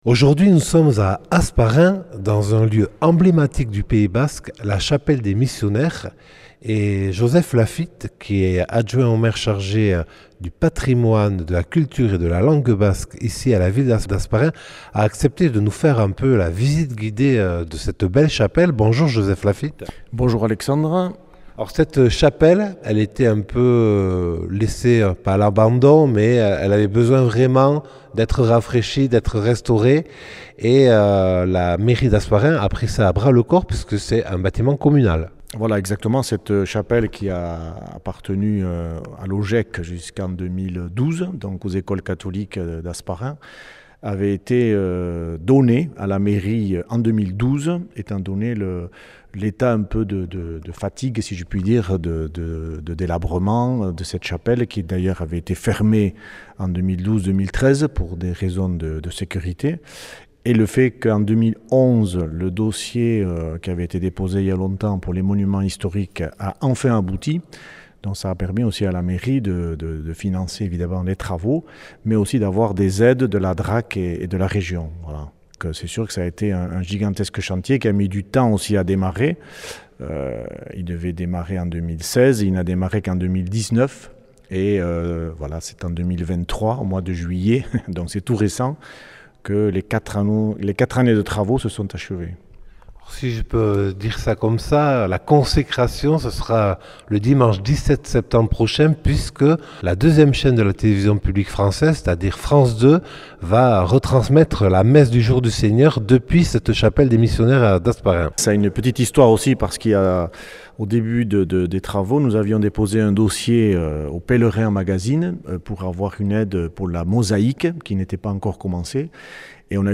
Après quatre ans de travaux, la chapelle dédiée au Sacré-Coeur de Jésus est enfin réouverte au public : visite avec Joseph Lafitte, adjoint au maire d’Hasparren chargé de la culture, du patrimoine et de la langue basque.